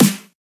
hammer.wav